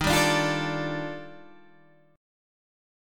EbM#11 chord